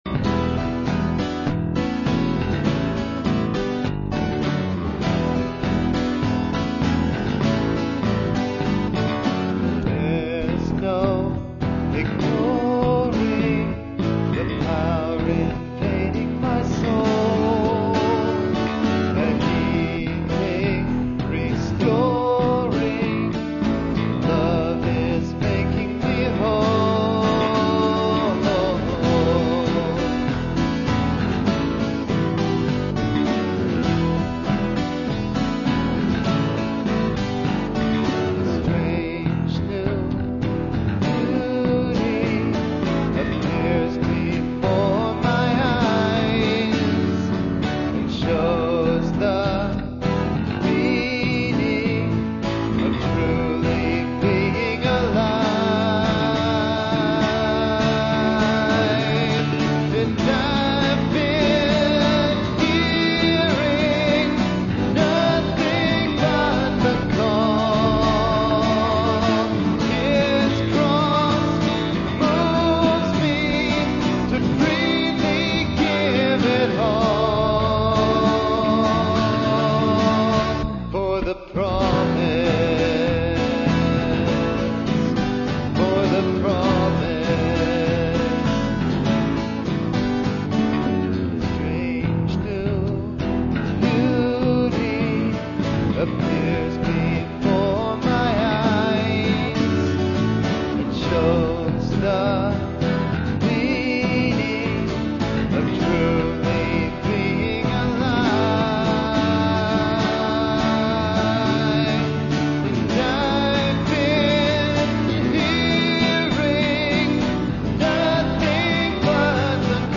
This is a moderately energetic worship song which focuses on God's redemptive work in our heart.